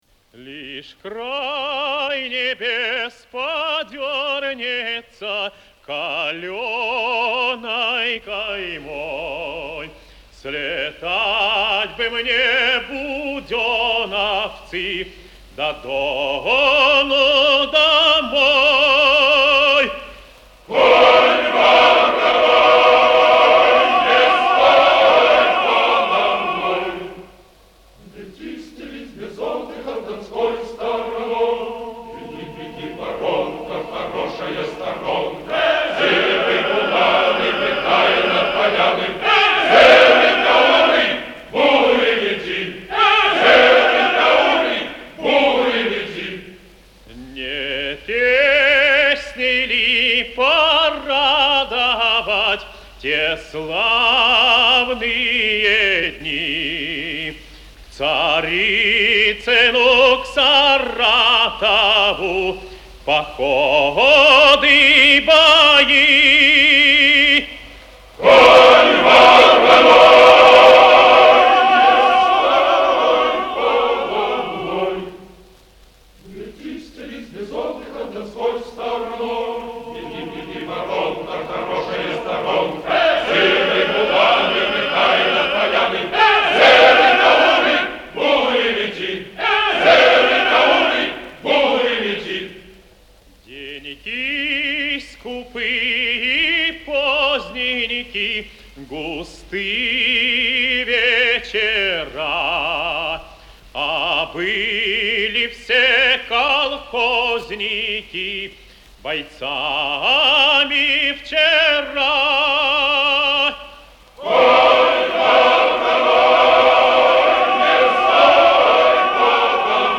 a'capella
хор